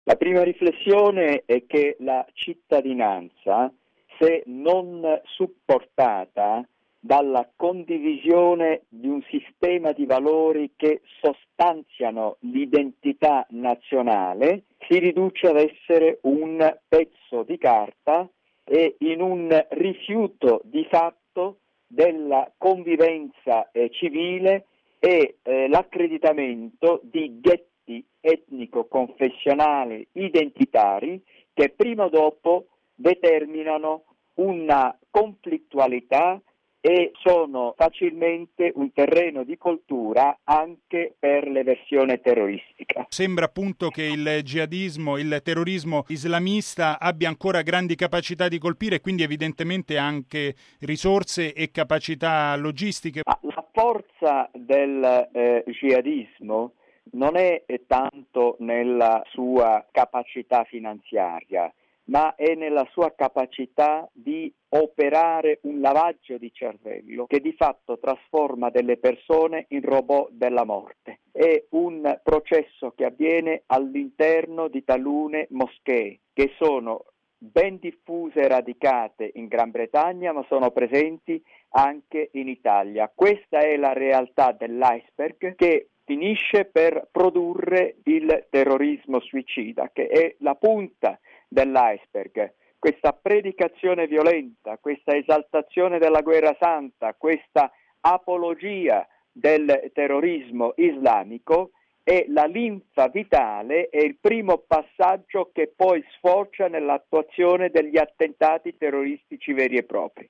ha chiesto l’opinione di Magdi Allam, vicedirettore del Corriere della Sera, profondo conoscitore del mondo islamico